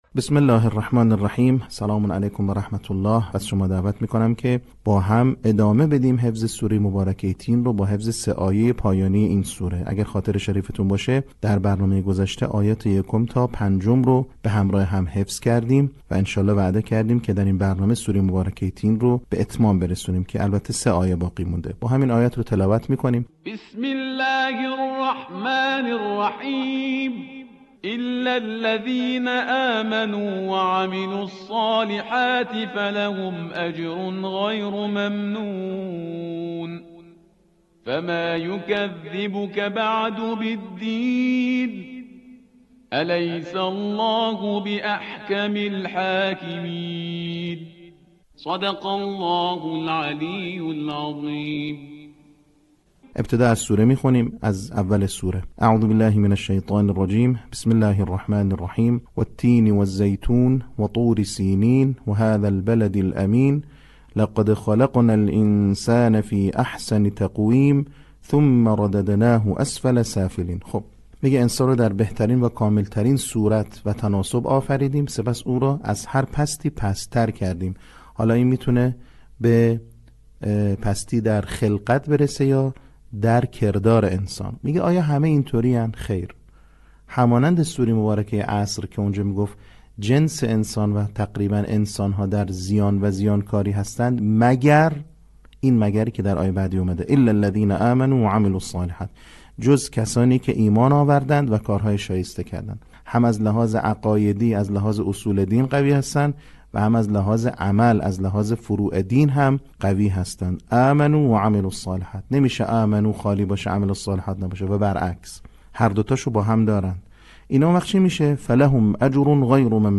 صوت | بخش دوم آموزش حفظ سوره تین